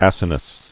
[ac·i·nus]